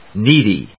音節need・y 発音記号・読み方
/níːdi(米国英語), ˈni:di:(英国英語)/